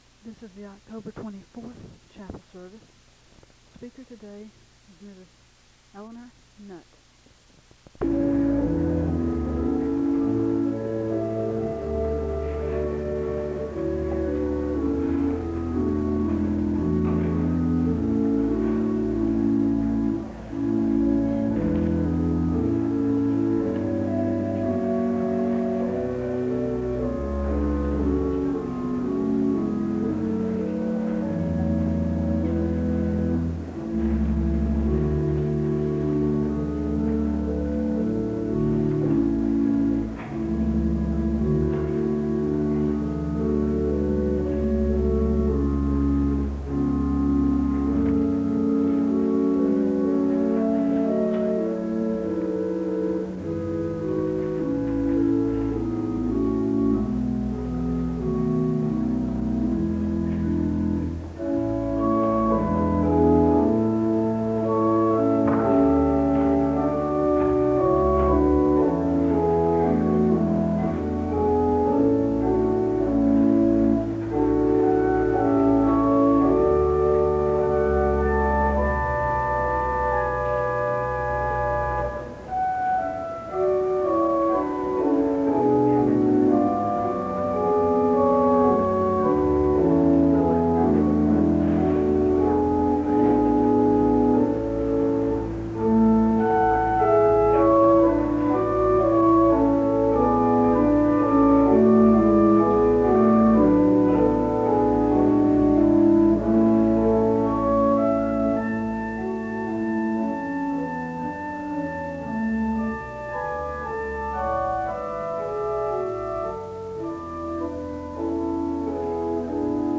Download .mp3 Description Audio quality is low.
The service begins with organ music (00:00-02:32).
The choir sings the anthem (08:35-11:04).
Lay ministry